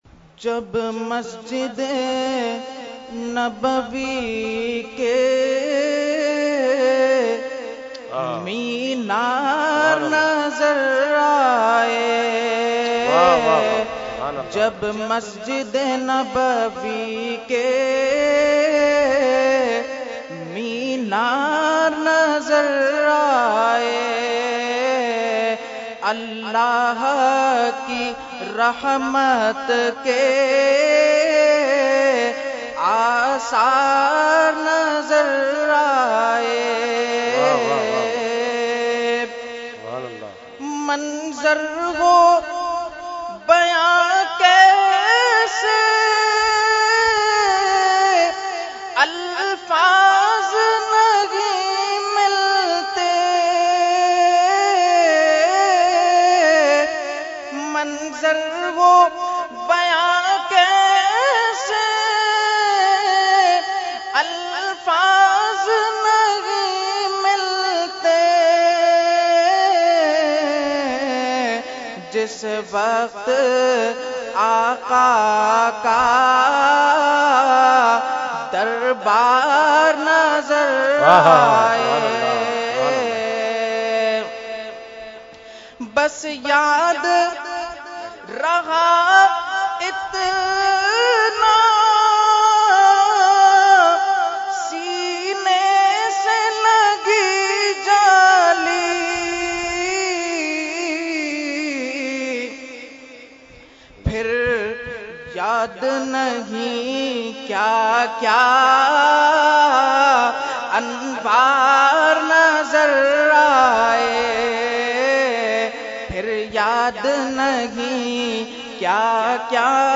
Category : Naat | Language : UrduEvent : Urs Ashraful Mashaikh 2017